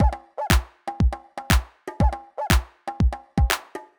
ITA Beat - Mix 5.wav